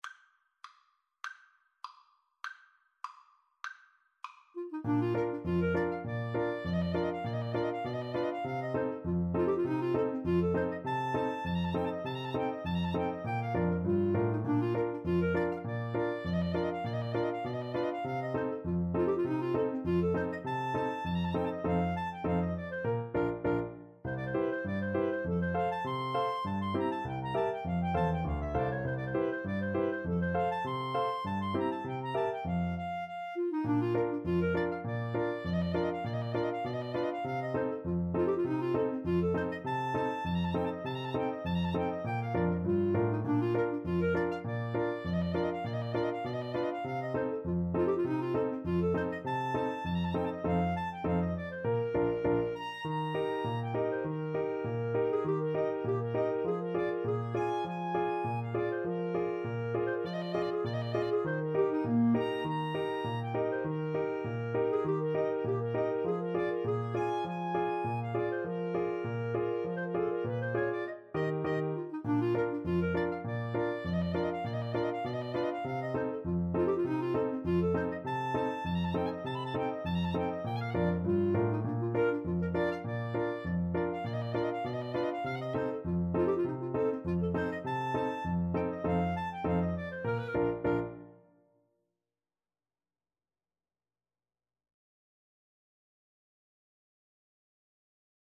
2/4 (View more 2/4 Music)